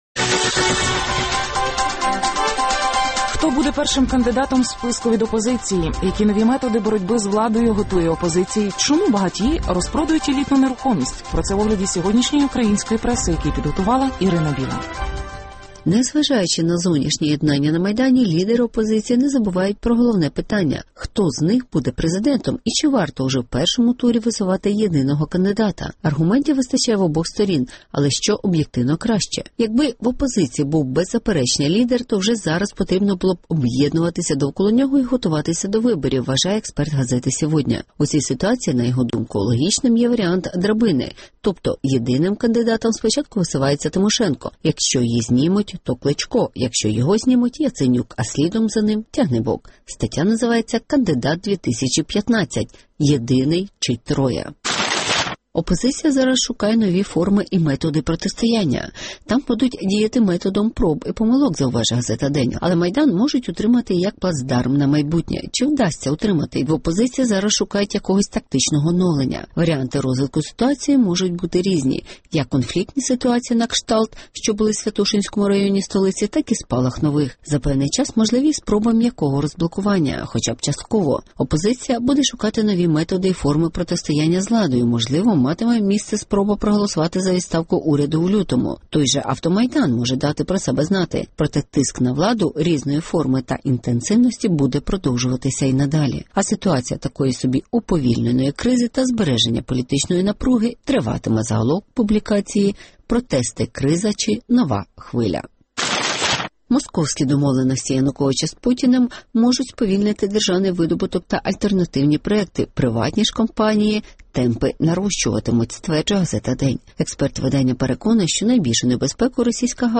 Майдан шукає нові методи тиску на Януковича (огляд преси)